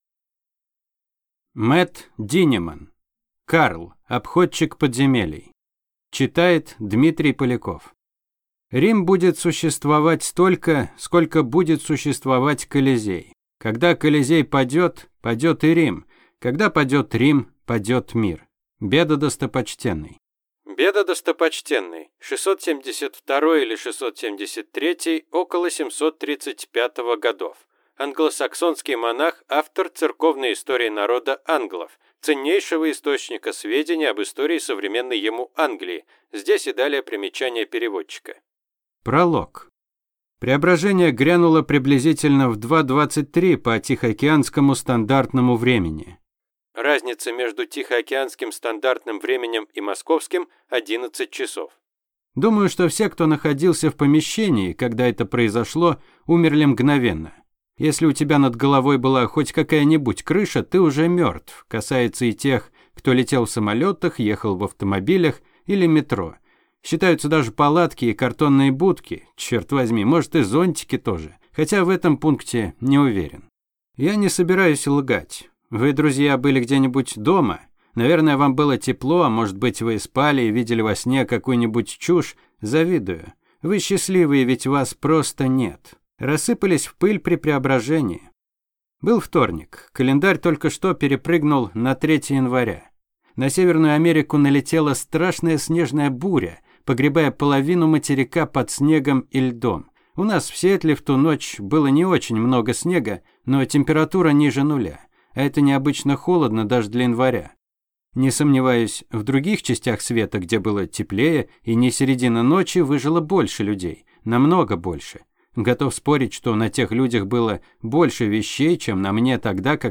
Аудиокнига Карл – обходчик подземелий | Библиотека аудиокниг